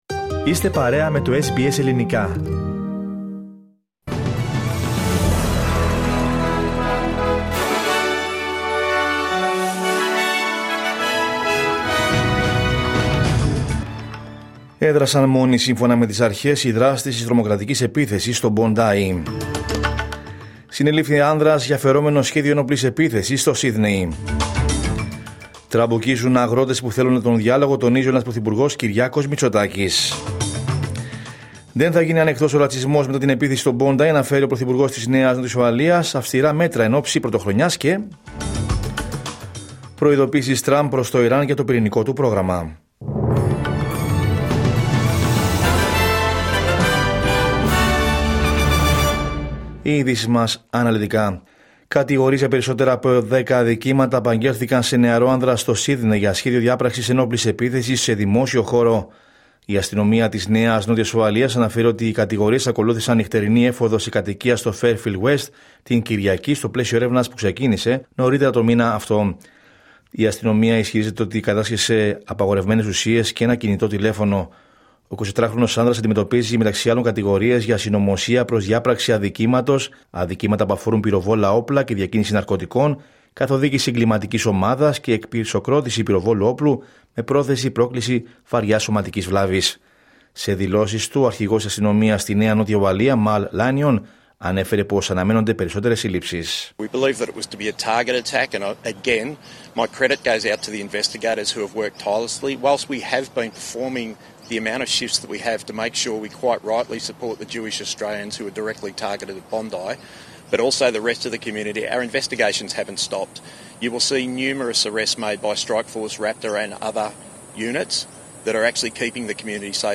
Ειδήσεις: Τρίτη 30 Δεκεμβρίου 2025